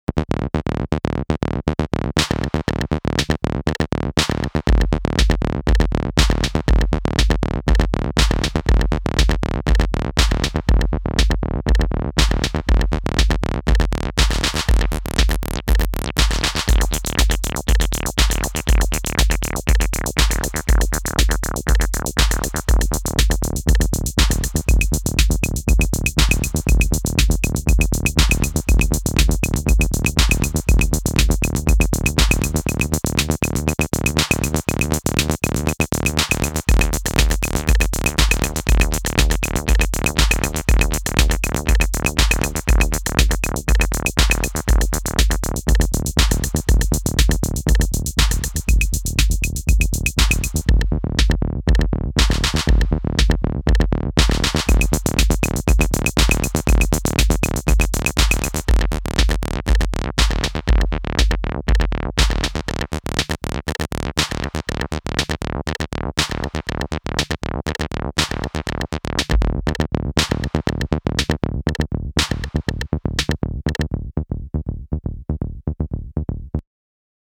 Bassbot with Roland Tr808